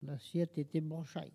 Elle provient de Bouin.
Locution ( parler, expression, langue,... )